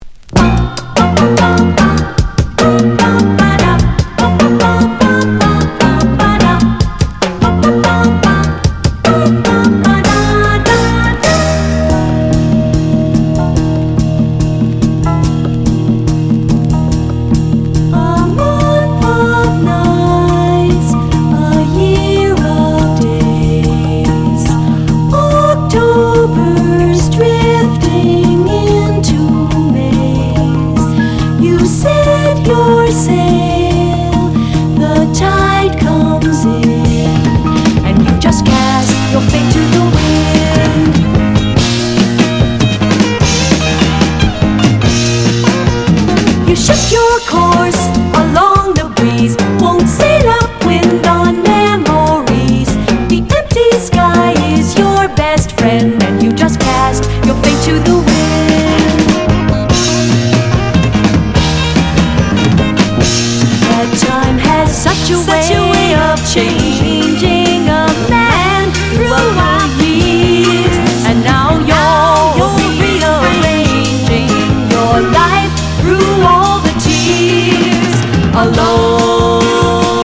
ジャジー＆ラテン・ソフトロック好盤！